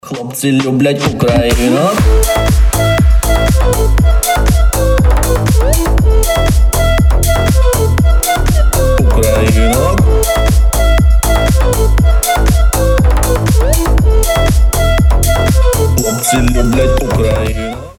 веселые
дудка
танцевальные